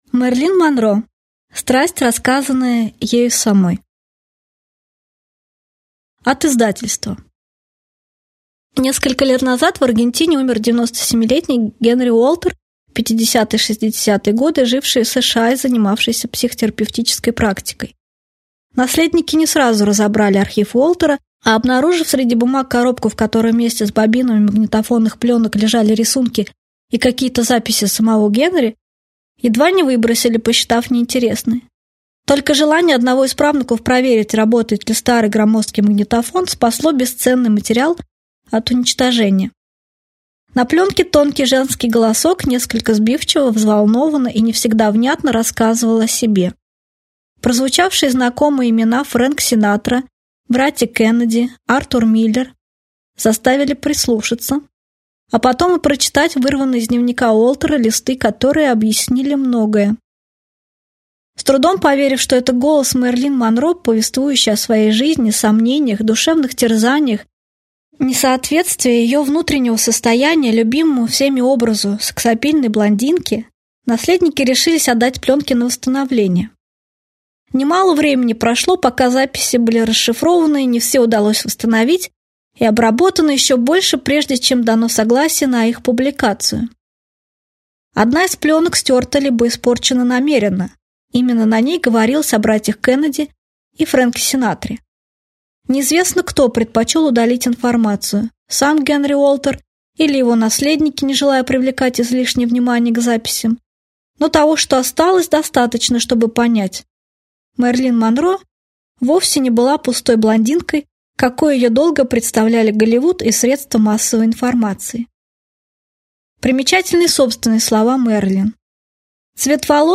Аудиокнига Мэрилин Монро. Страсть, рассказанная ею самой | Библиотека аудиокниг